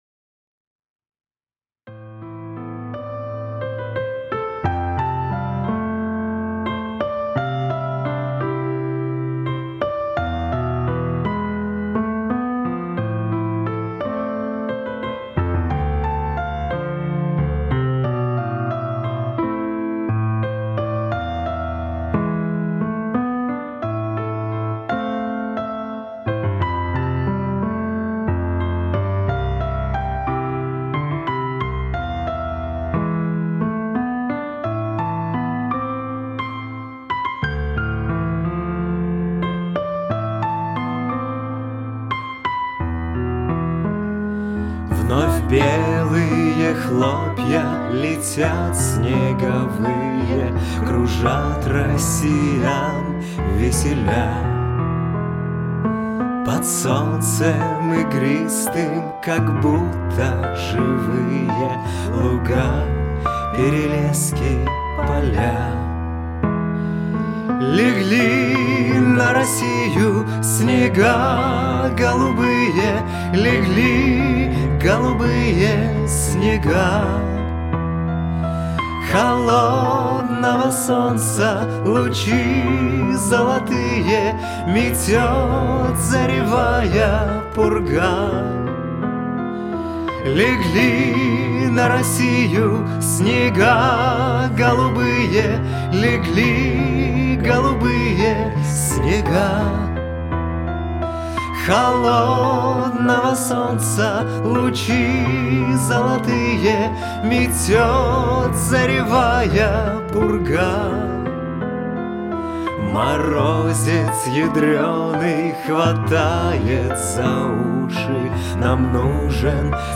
"Снега голубые" (современный романс, фортепиано)
Мне кажется, что имеющаяся фортепианная минусовка слишком "плотная" и не очень точно сочетается с вокалом.
Потом с помощью того же Vocai Rider сблизил динамику фортепианной партии и вокала. Ну и, разумеется, то что обычно используют при сведении: эквалайзер, компрессор, ревер, де-ессер - всё это на вокале. Фортепианную партию не обрабатывал, так как, такое впечатление, что электронное пианино и так "навалило" и компрессии, и объема.